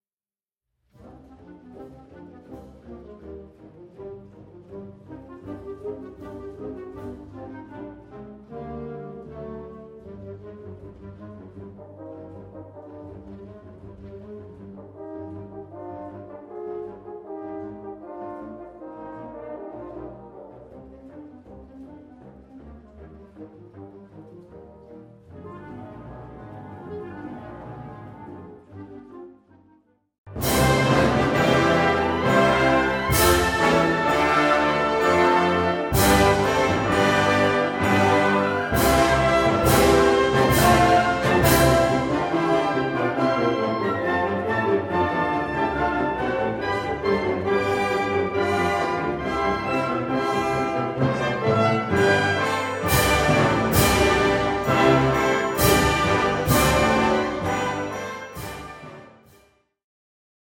C minor, C Major（原調）
比較的原曲に忠実な編曲です。
金管にはスタミナは要りませんが、透明感のある音色が必要です。